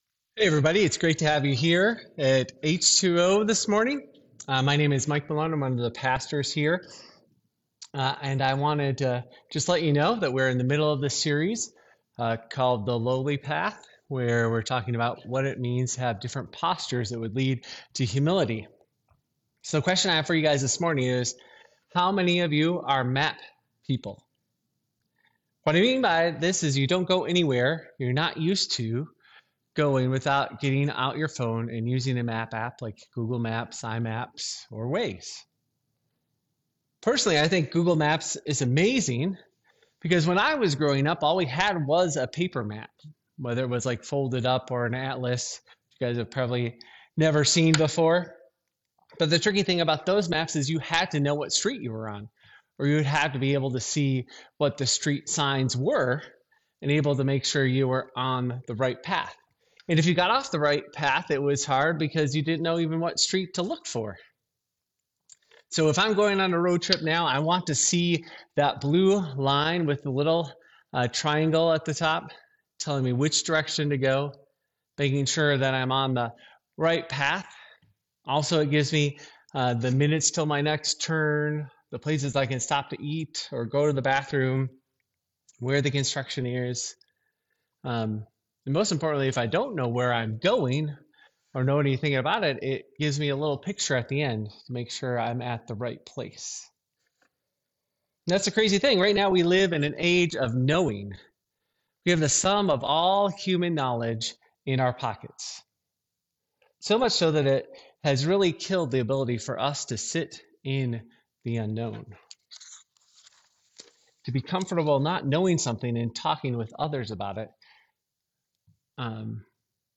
In this sermon series, we’re walking through the book of Genesis—the “Book of Beginnings,” but also the book of very human failures.